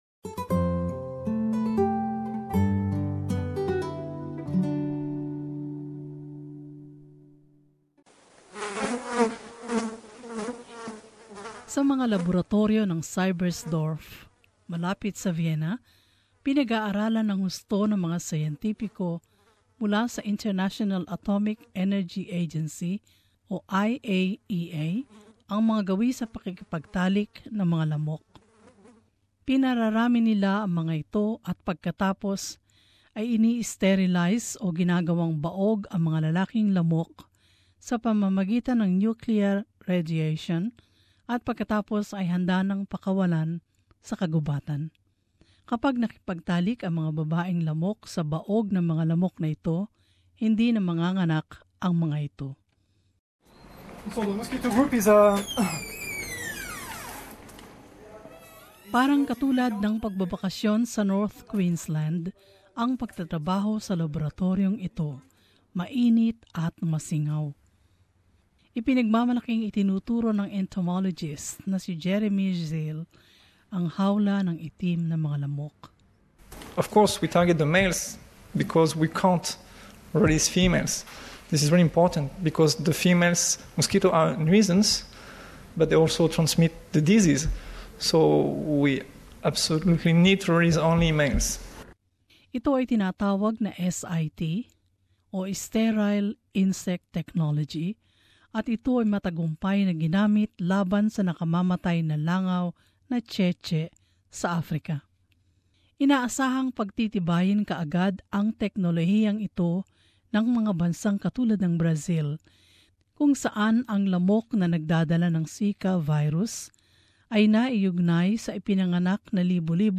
In this report, scientists at the International Atomic Energy Agency in Austria think they have the answer: